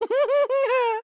*咯咯笑*